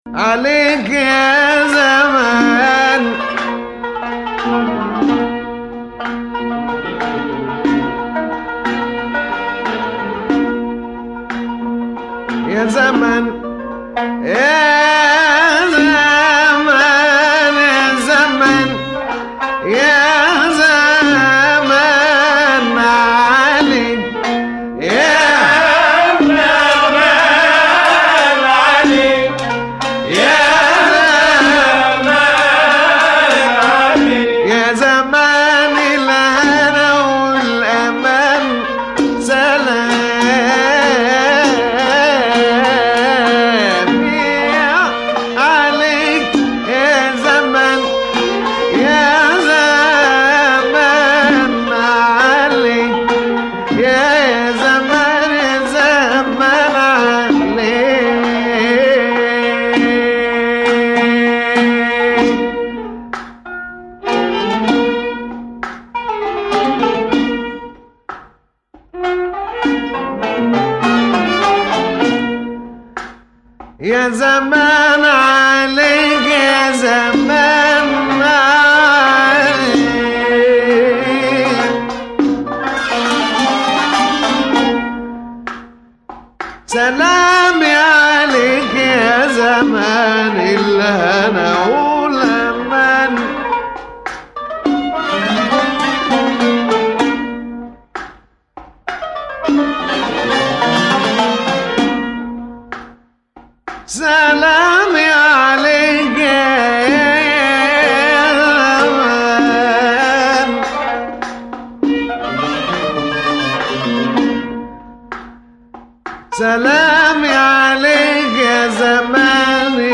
Jiharkah
Jins Jiharkah